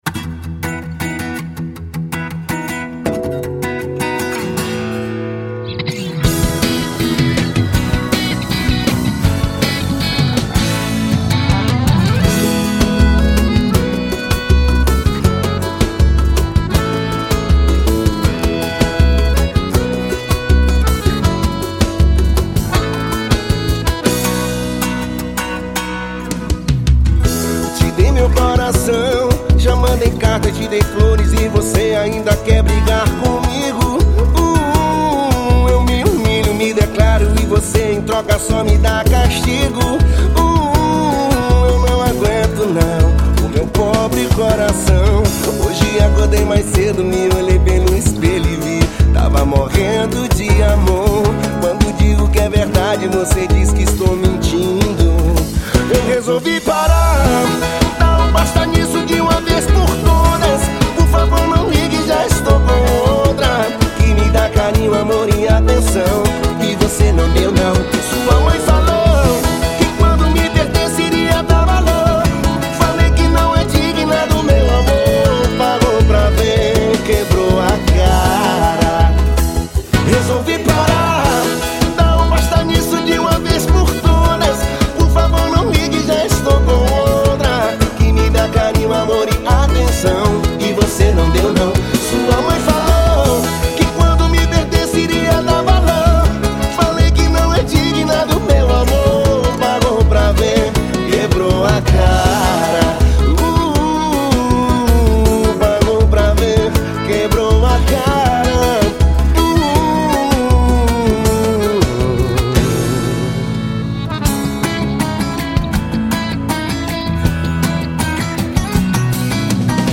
EstiloPagode